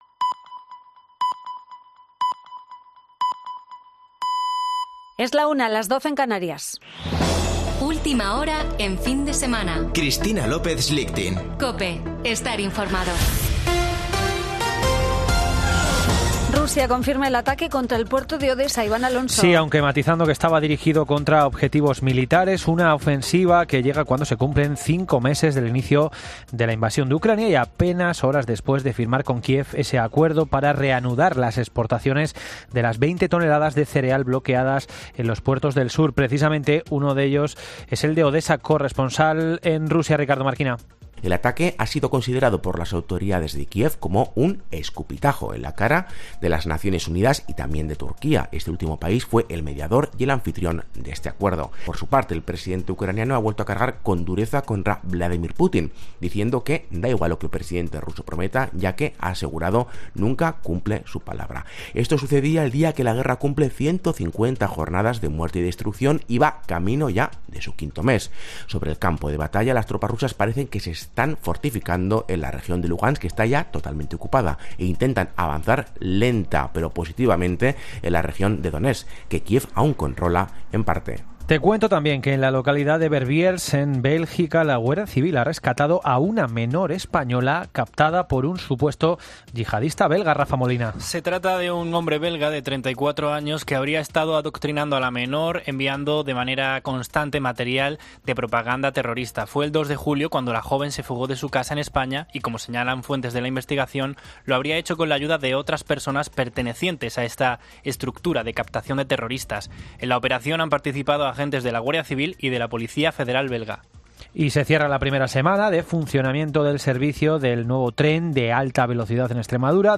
Boletín de noticias de COPE del 24 de julio de 2022 a la 13:00 horas